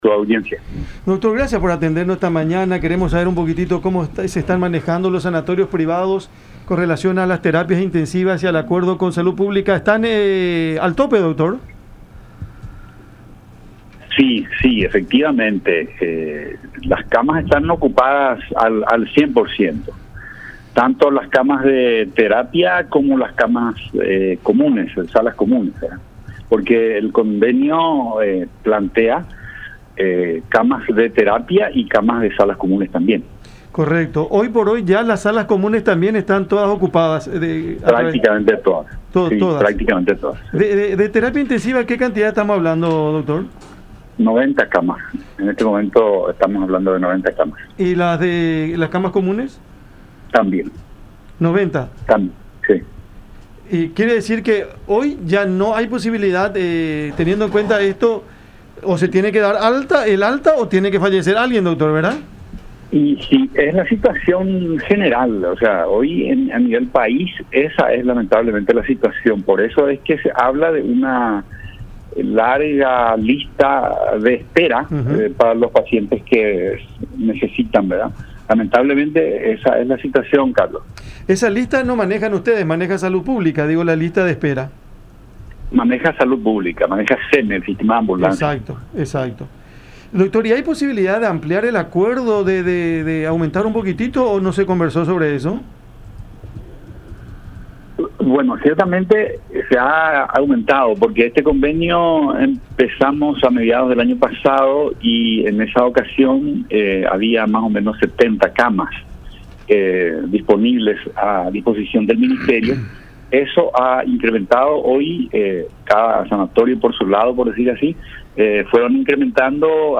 en charla con La Unión